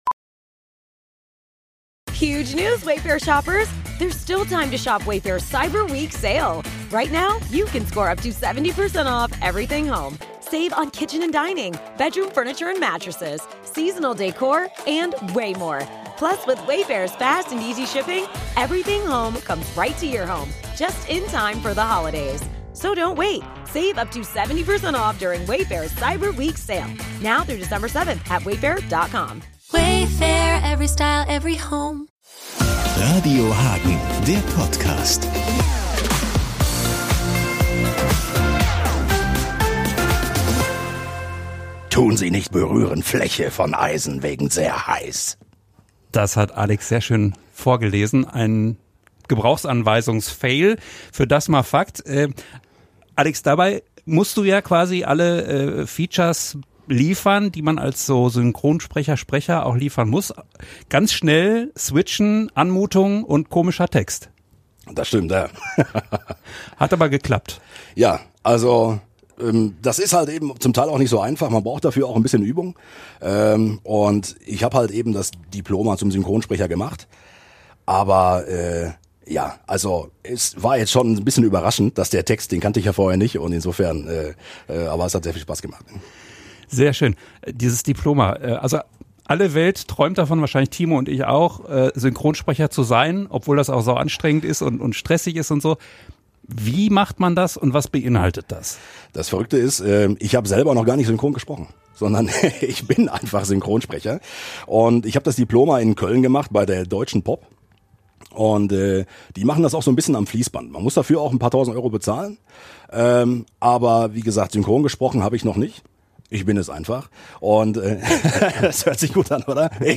Hier das Gespräch mit